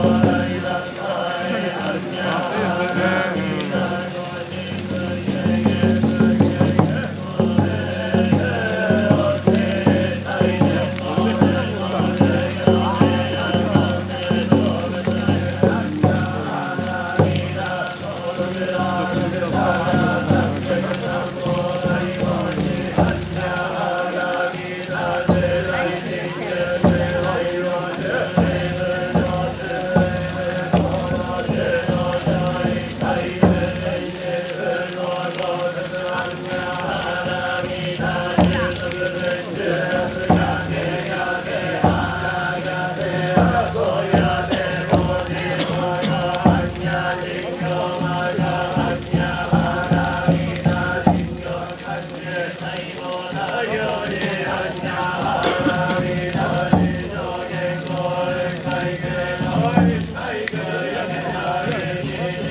Fire walking festival